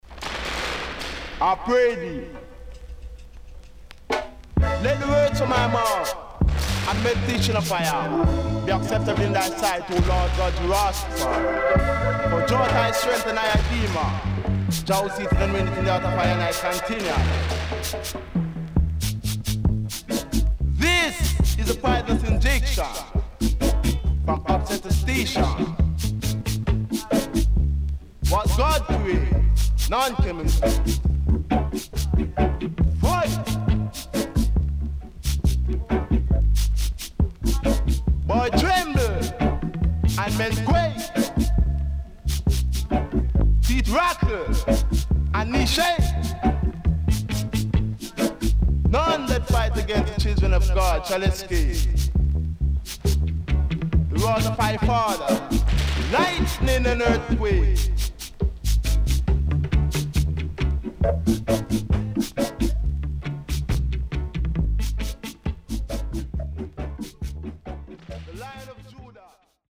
HOME > REGGAE / ROOTS
SIDE A:少しチリノイズ入ります。